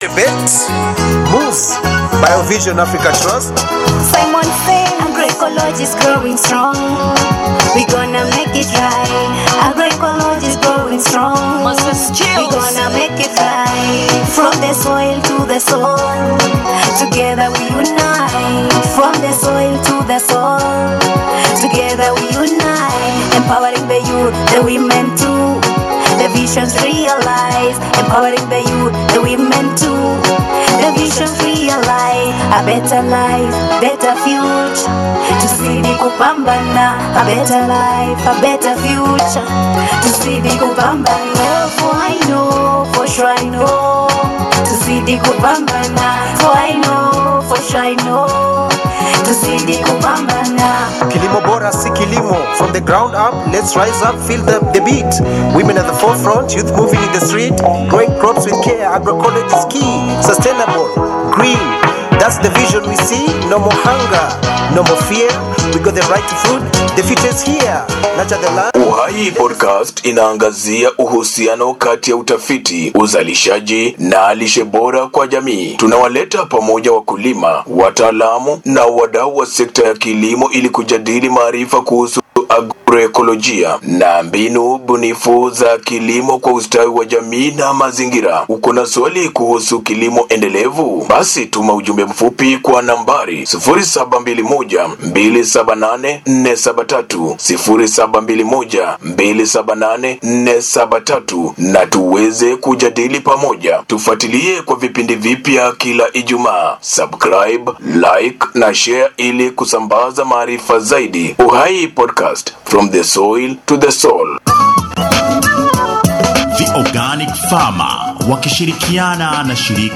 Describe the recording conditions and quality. Did you miss our live discussion on Water Harvesting?